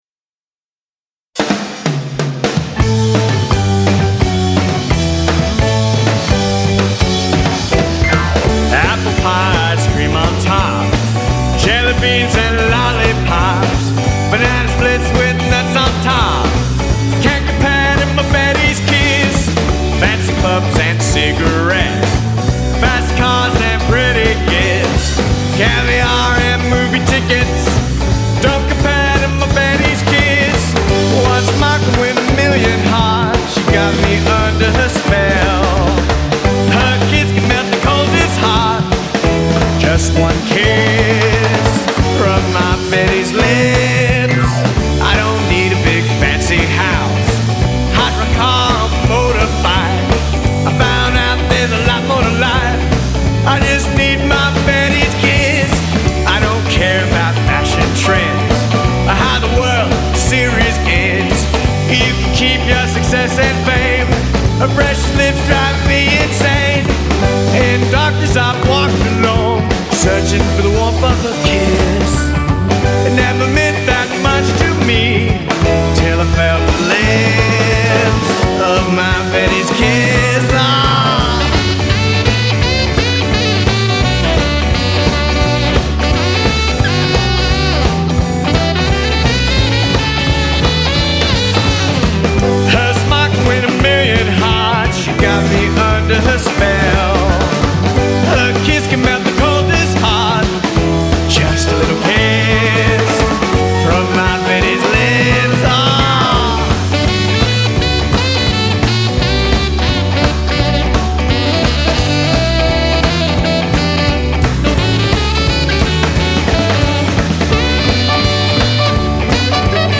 Vocals
Bass
Drums